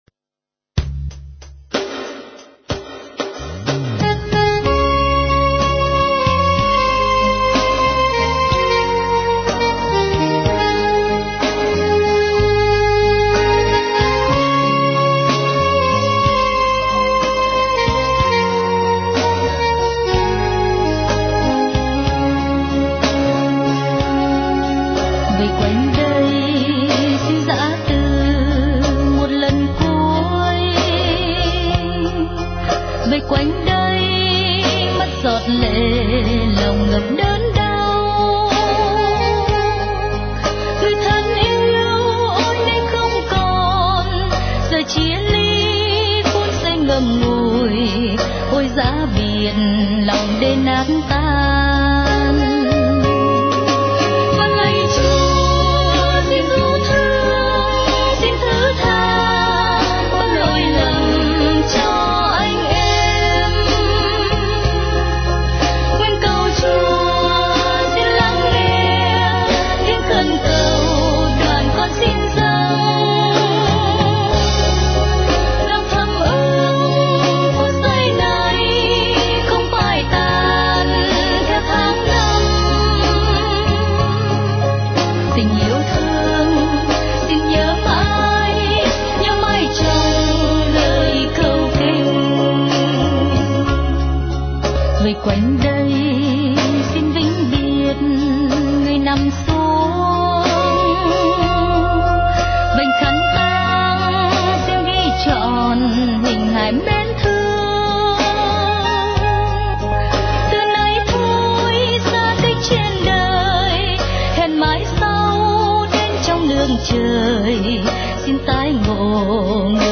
* Thể loại: Cầu hồn